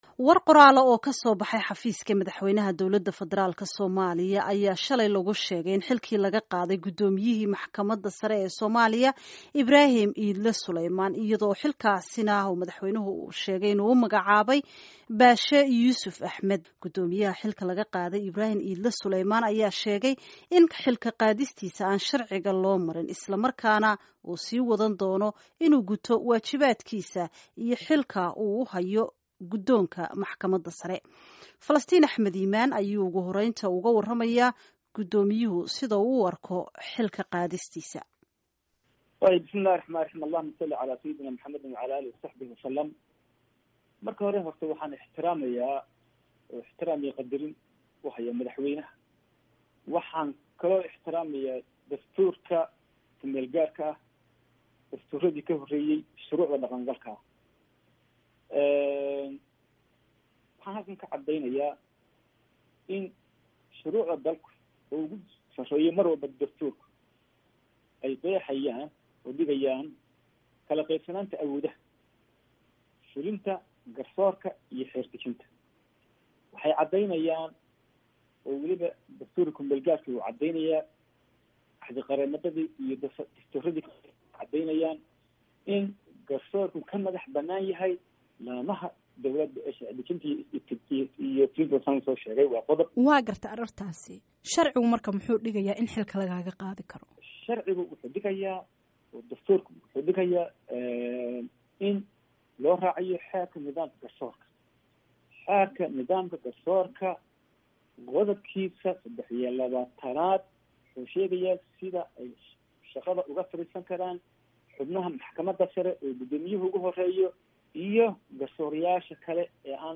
Wareysi Gudoomiyaha Maxkamada Sare